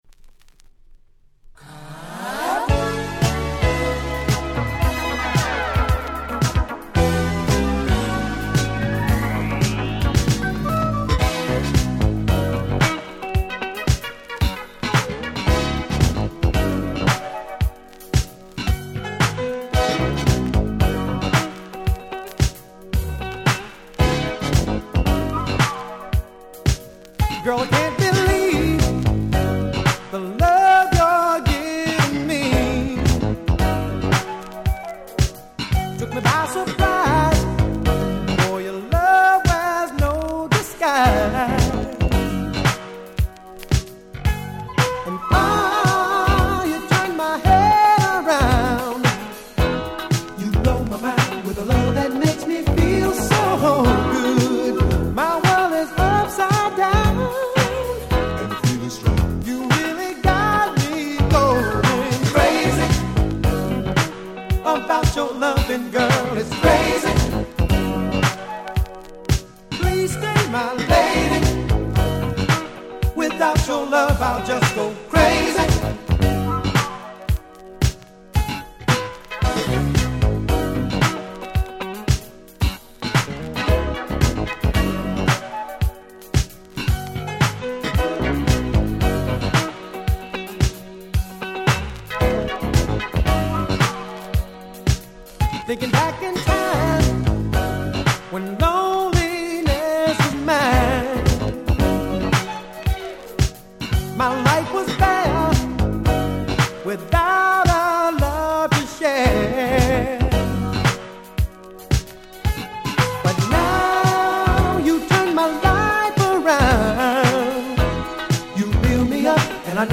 83' Nice Disco Boogie !!
人気Dance Classics !!
爽やかなオケに爽やかな歌声、もうこれ以上望む事はございません(笑)